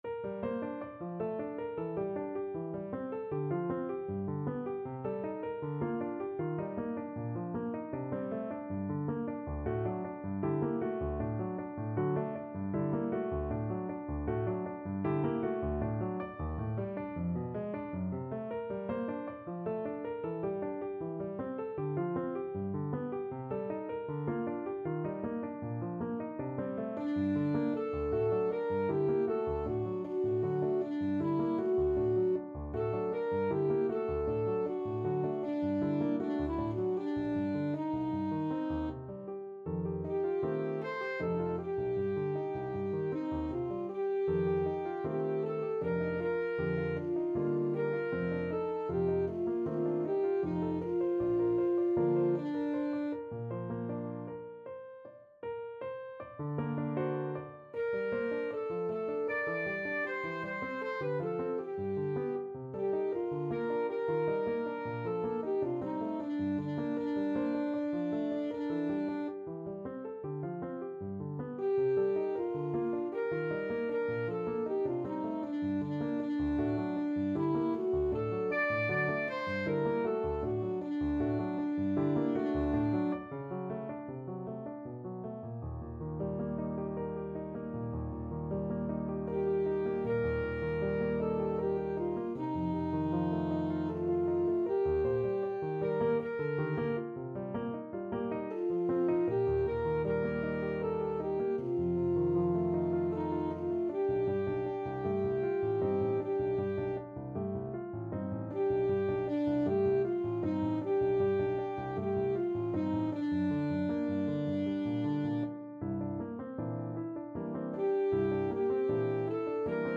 Alto Saxophone version
Alto Saxophone
3/4 (View more 3/4 Music)
Classical (View more Classical Saxophone Music)